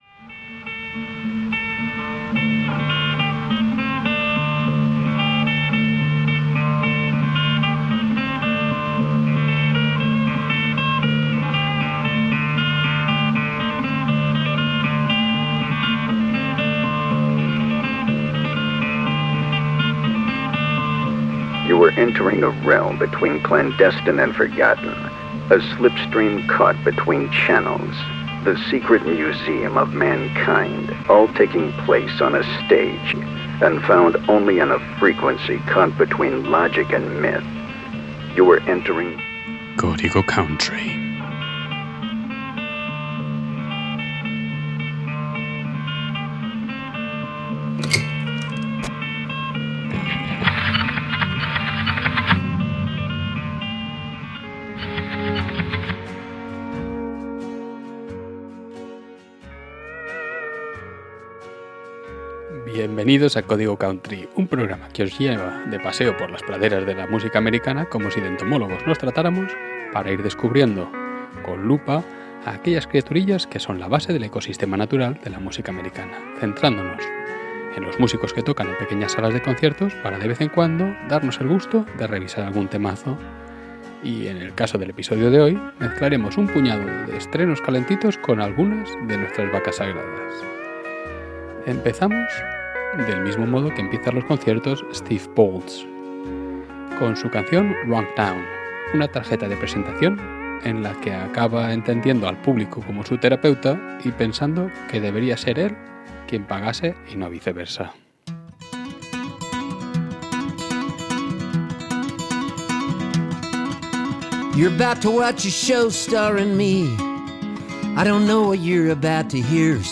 Hoy nos adentramos en un viaje musical lleno de estrenos frescos y clásicos atemporales.